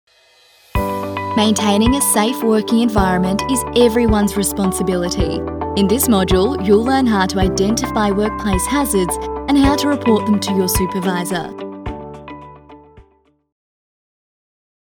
Female
English (Australian)
Yng Adult (18-29), Adult (30-50)
E-Learning